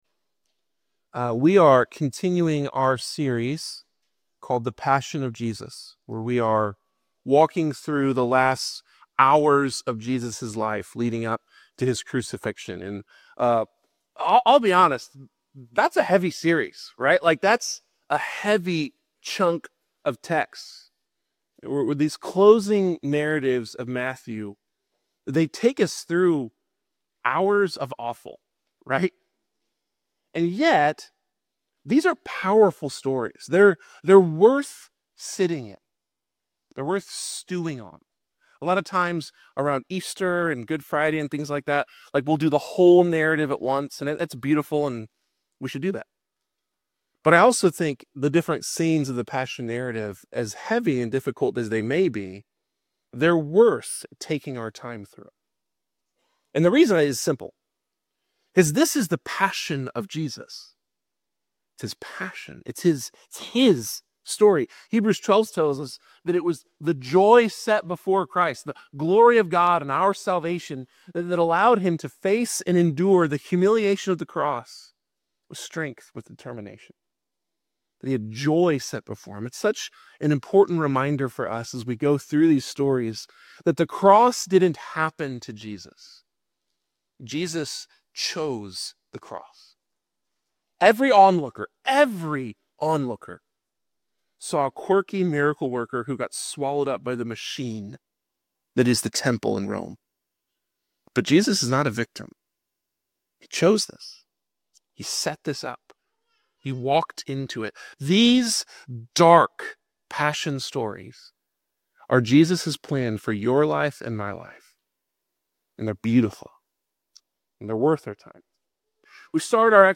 This sermon reveals how Christ transforms deniers into disciples through His sacrificial love and forgiveness.